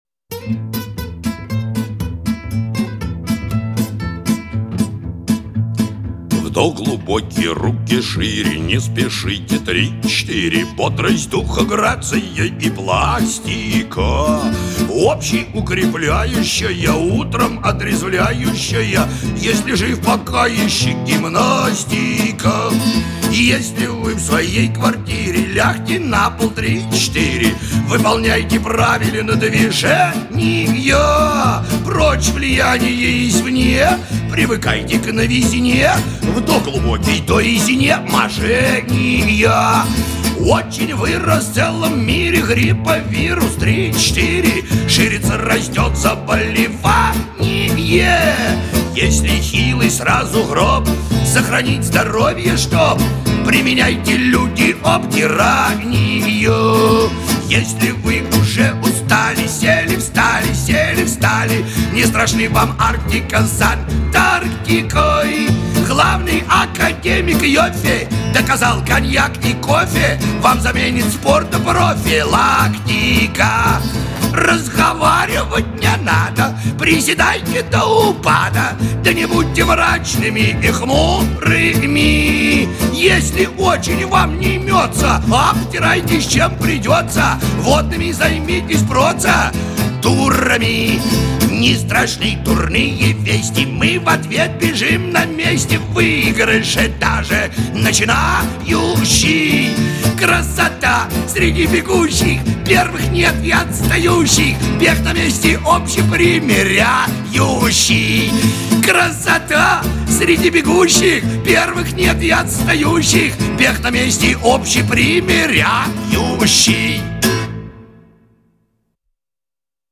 Авторская песня
Режим: Stereo